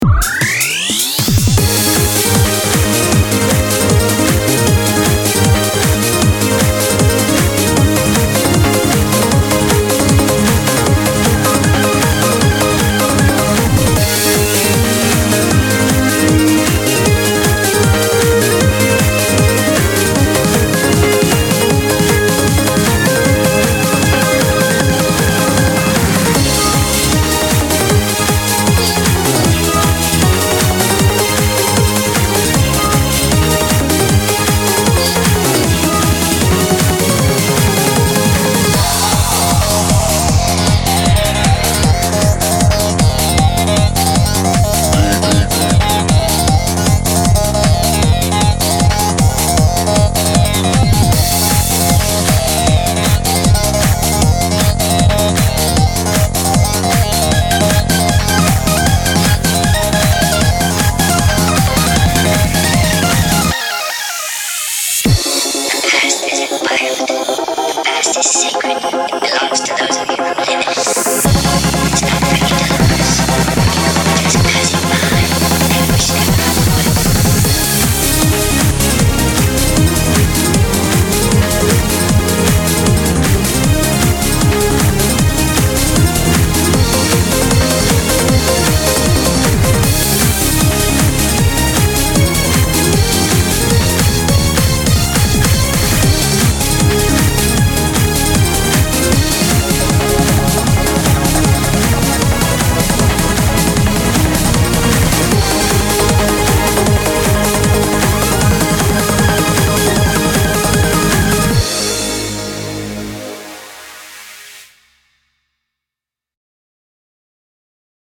BPM155
Audio QualityPerfect (High Quality)
CommentsGenre: L.E.D.+TAKA TANGENTIAL